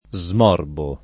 smorbo [ @ m 0 rbo ]